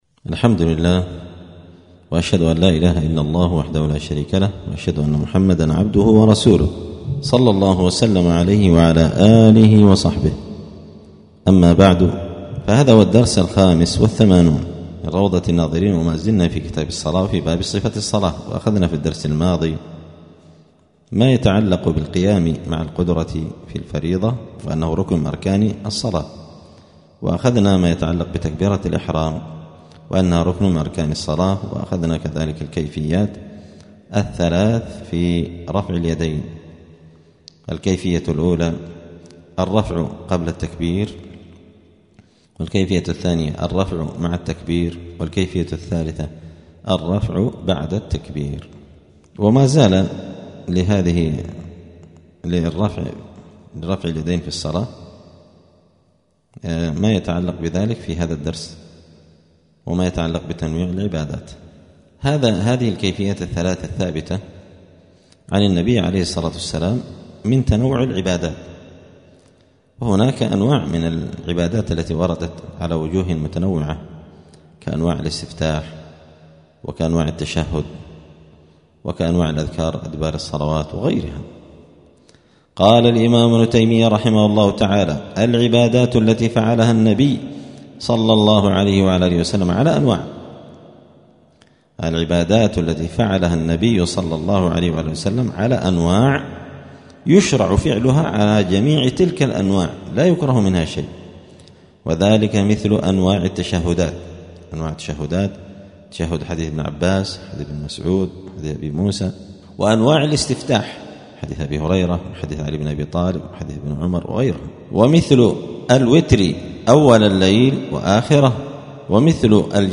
*الدرس الخامس والثمانون (85) {كتاب الصلاة باب صفة الصلاة فوائد تنويع العبادات}*
دار الحديث السلفية بمسجد الفرقان قشن المهرة اليمن